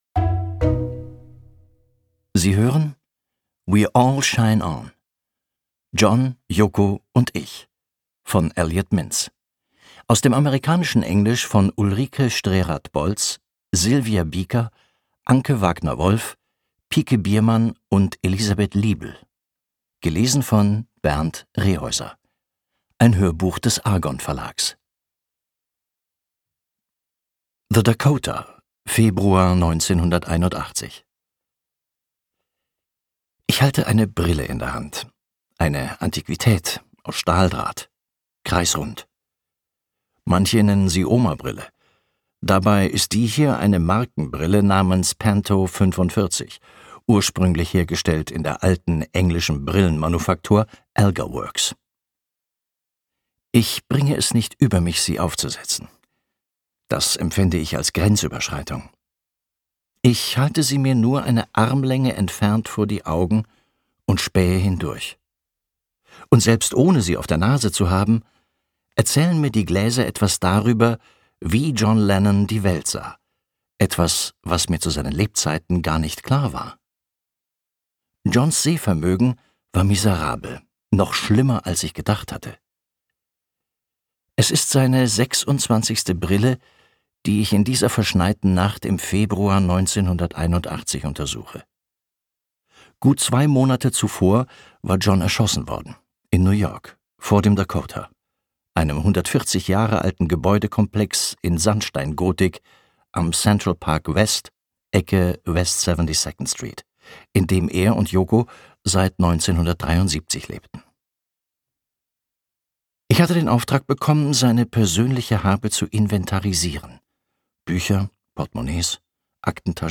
2024 | 1. Auflage, Ungekürzte Ausgabe